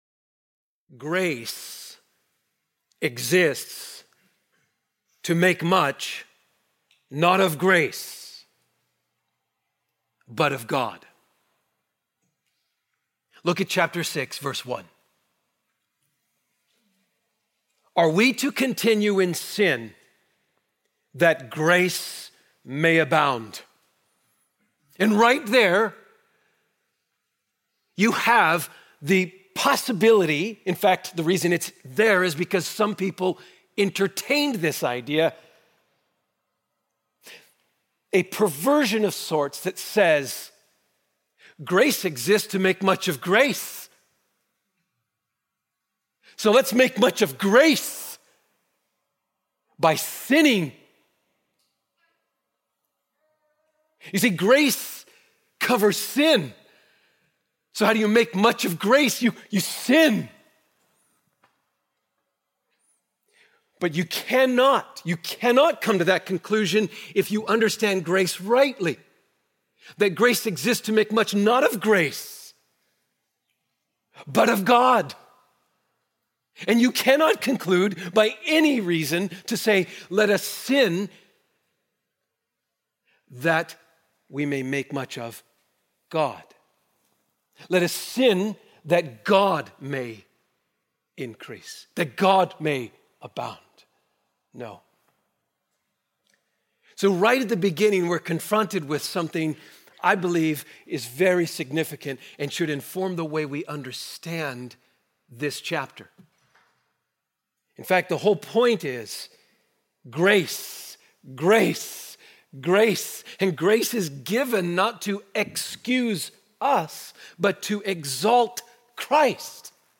Sermons - Trinity Bible Church